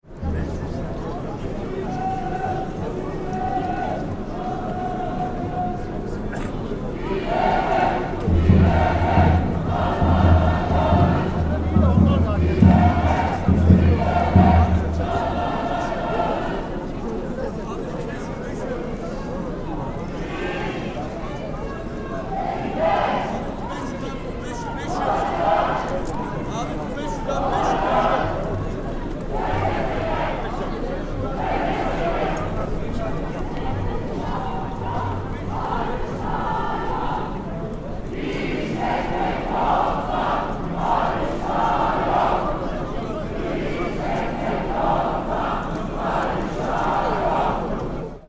The lines are long and the marchers warm up by shouting call and response slogans. The atmosphere is enthusiastic and victorious: